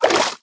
swim4.ogg